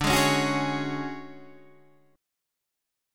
D Major 7th Flat 5th